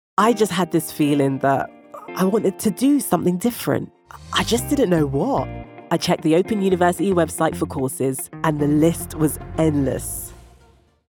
Female
Additionally, with her Nigerian heritage, she can deliver a bold West African accent, adding even more depth to her vocal range.
Urban
University Student Ad
All our voice actors have professional broadcast quality recording studios.